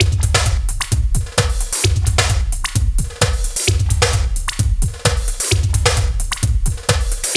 TEK NO LOOP 1.wav